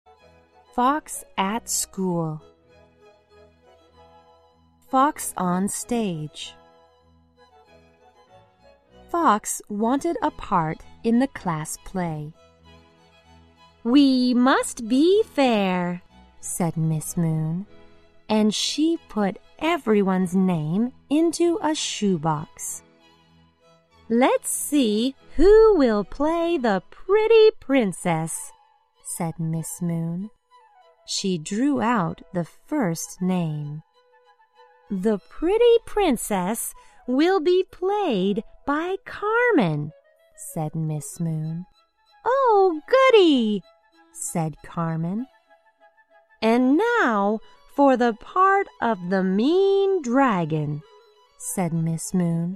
在线英语听力室小狐外传 第29期:小狐在学校的听力文件下载,《小狐外传》是双语有声读物下面的子栏目，非常适合英语学习爱好者进行细心品读。故事内容讲述了一个小男生在学校、家庭里的各种角色转换以及生活中的趣事。